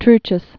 (trchəs)